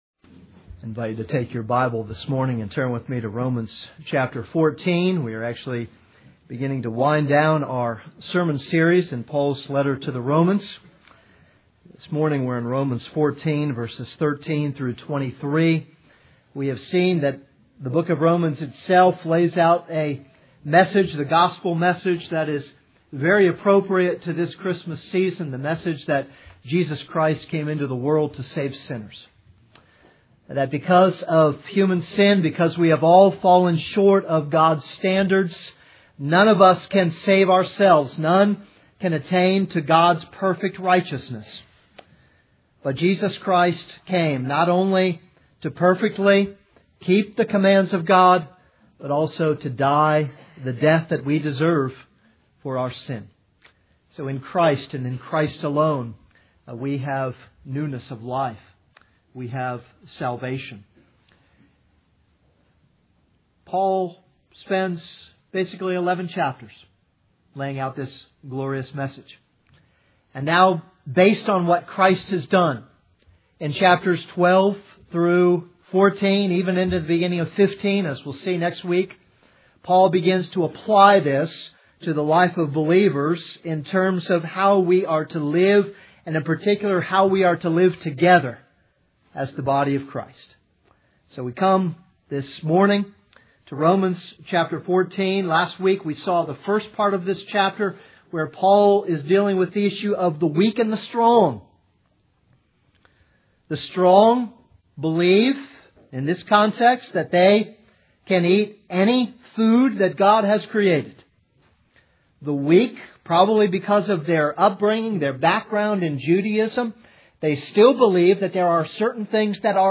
This is a sermon on Romans 14:13-23.